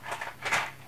multiple matches.wav
Recorded in a small apartment studio with a Tascam DR 40
multiple_matches_M6d.wav